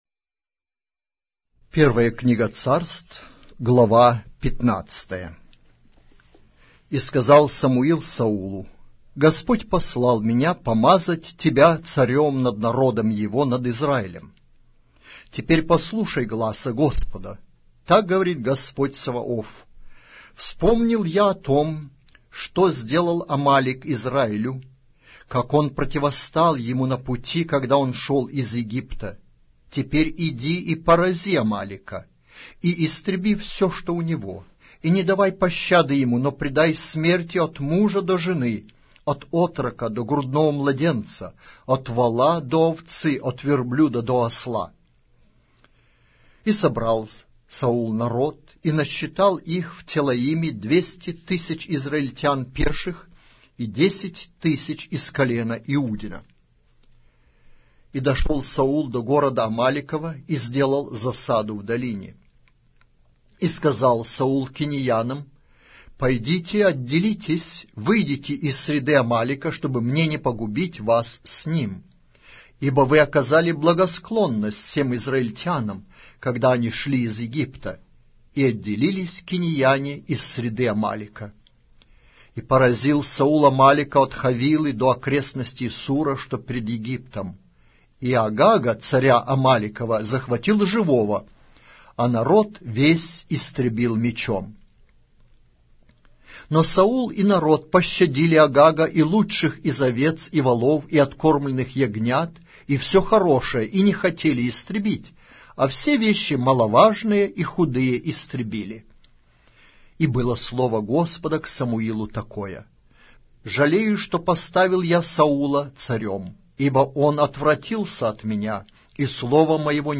Глава русской Библии с аудио повествования - 1 Samuel, chapter 15 of the Holy Bible in Russian language